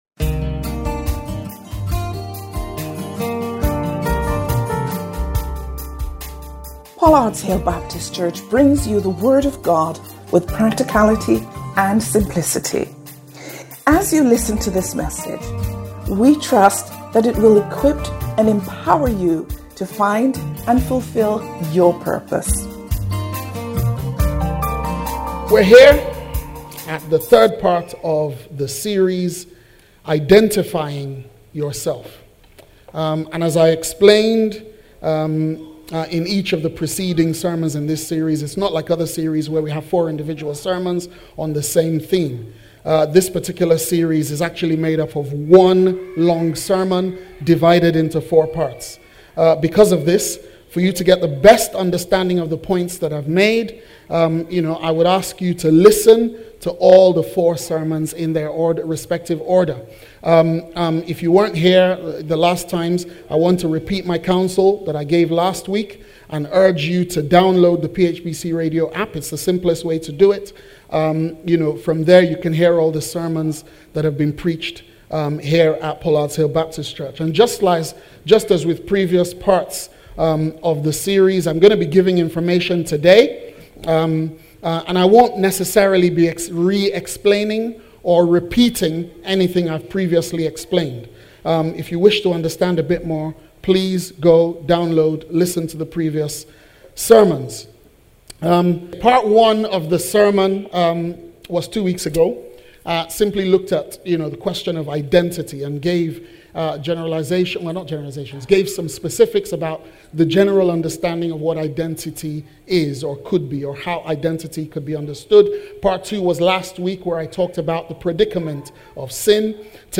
Sermons – Pollards Hill Baptist Church